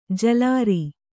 ♪ jalāri